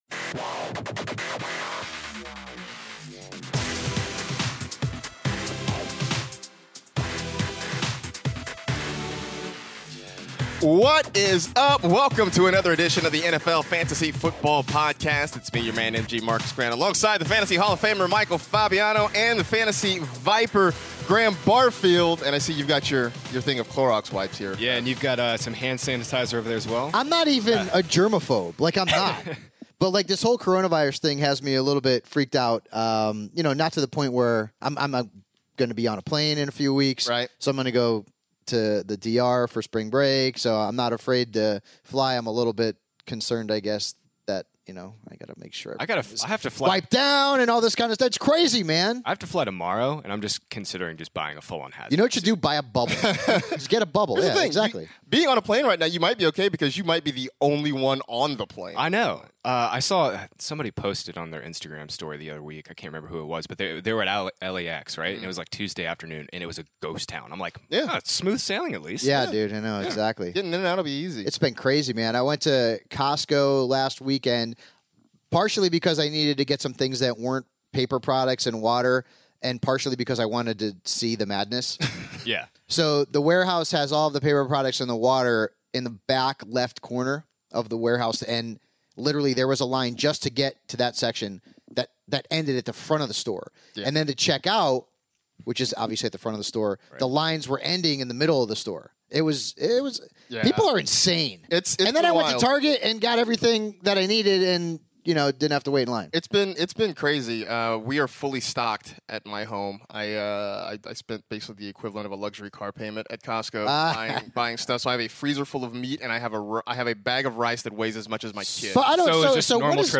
Next, Los Angeles Chargers running back Austin Ekeler joins the show (17:32)! Then, the guys wrapped up the show with the best free agency fits for the top quarterbacks, running backs, receivers and tight ends (28:26).